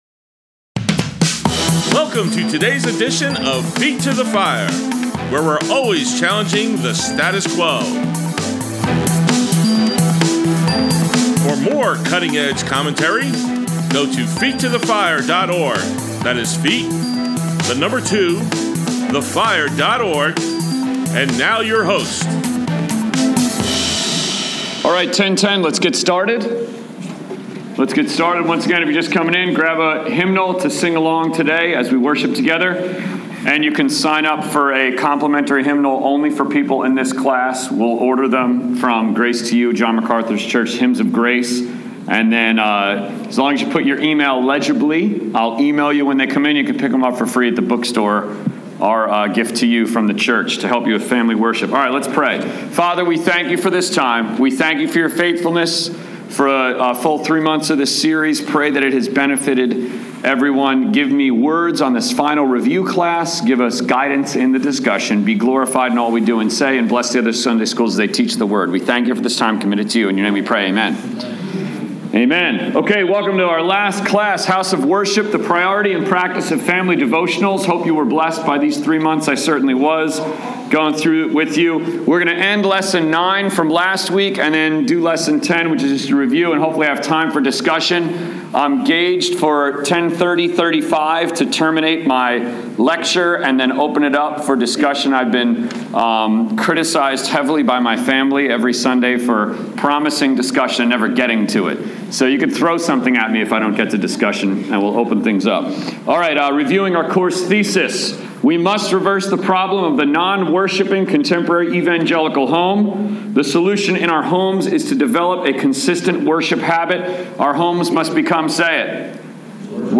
Grace Bible Church, Adult Sunday School, 3/20/16